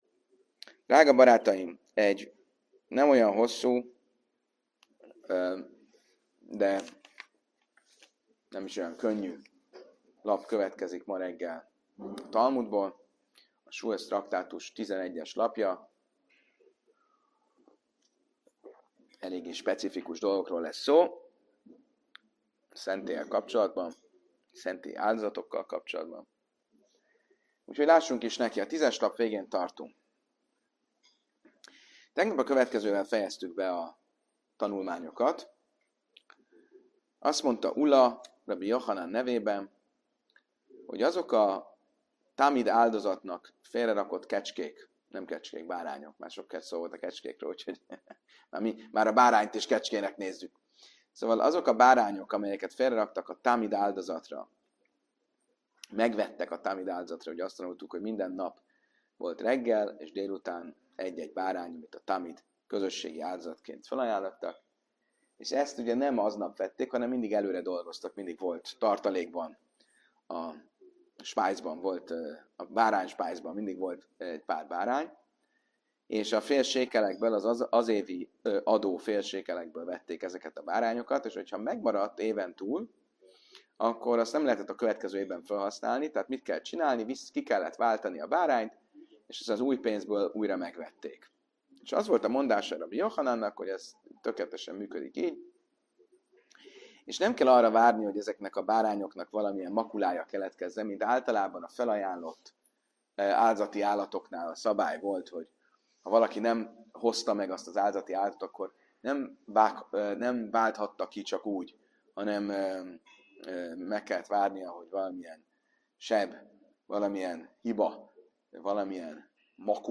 A rabbi józan humorral és táblás illusztrációval vezeti végig a hallgatót e talmudi labirintuson.